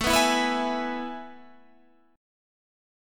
AM7sus2 chord